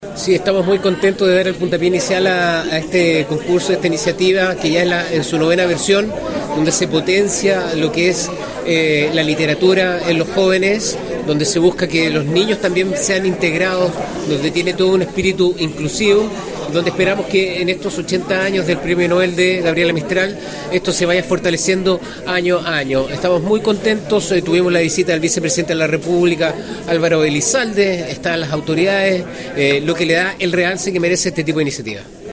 VICEPDTE-ELIZALDE-Cristobal-Julia-Gobernador-Regional.mp3